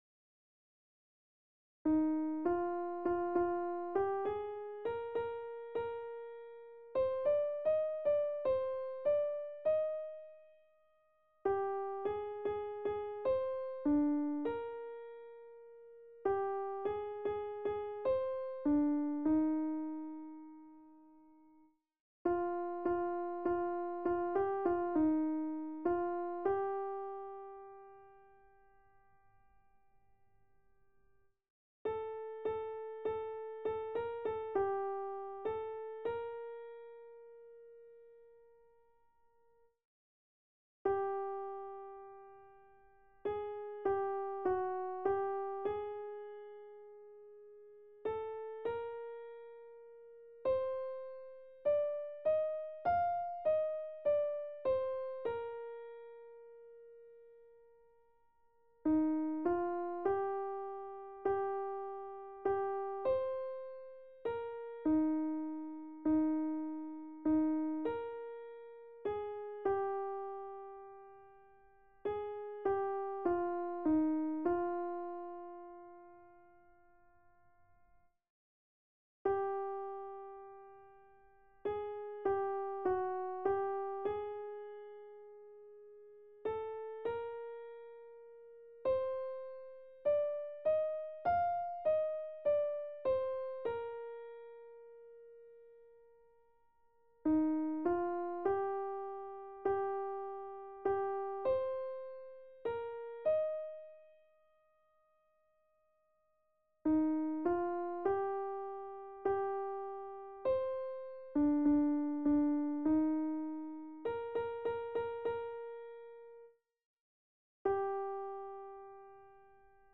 Noël_Blanc-Soprano.mp3